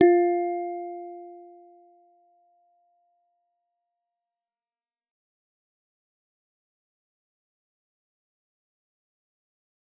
G_Musicbox-F4-f.wav